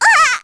Ophelia-Vox_Damage_01_kr.wav